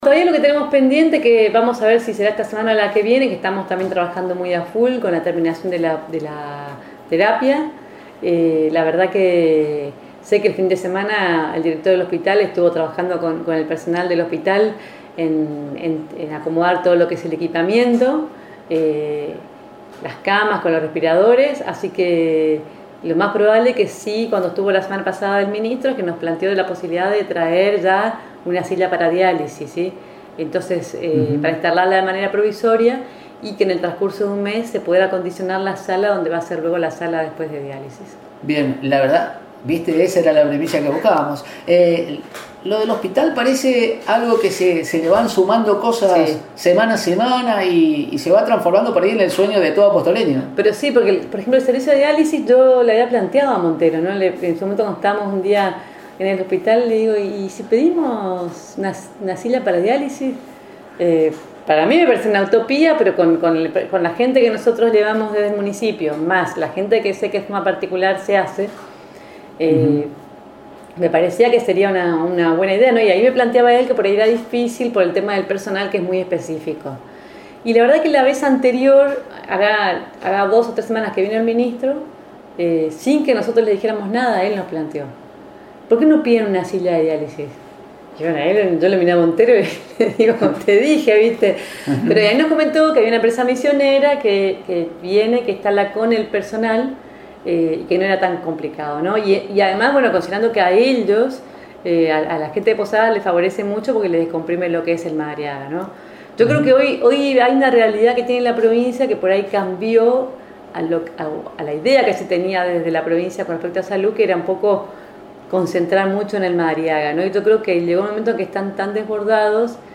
En visita a Radio Elemental la Intendente María Eugenia Safrán confirmó la pronta inauguración de la sala de Terapia intermedia e Intensiva en esta localidad y, en exclusiva adelantó la instalación de sillones de hemodiálisis que serán presentados ese día mostrando su satisfacción por los logros y destacando el servicio y comodidad que significará para pacientes renales de la zona Sur.